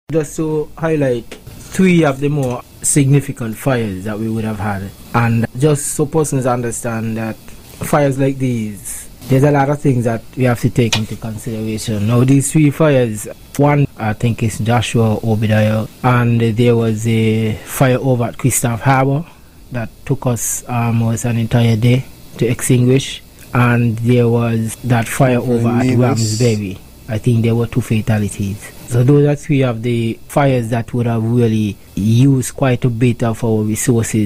During an interview